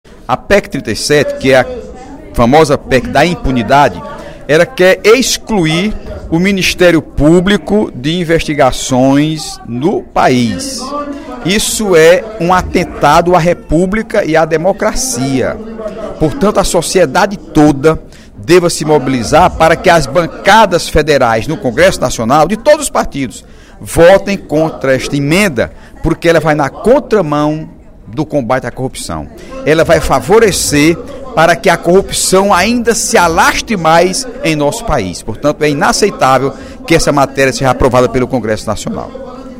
A afirmação foi feita pelo deputado Heitor Férrer (PDT), em pronunciamento no primeiro expediente da sessão desta sexta-feira (07/12).